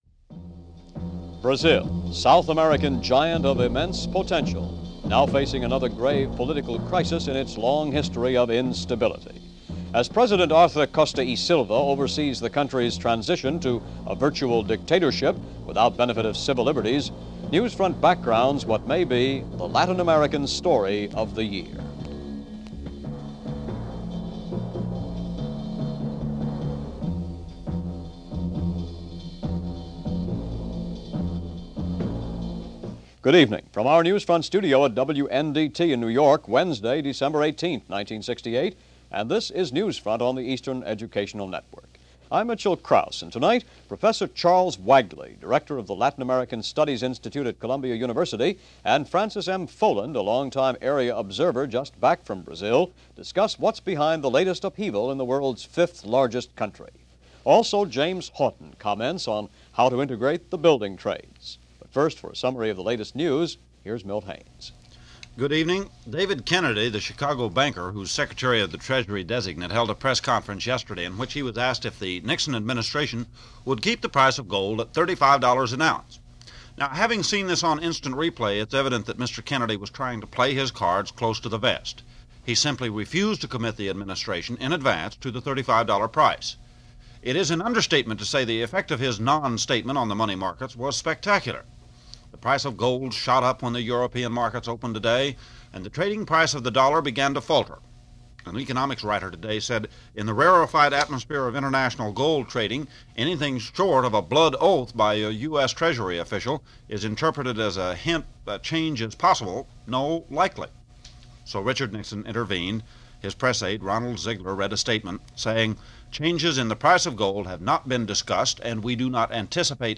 December 18, 1968 - Brazil: Land Of Instability - New York City - Land Of Endless Strikes - news for this day in 1968 - Past Daily.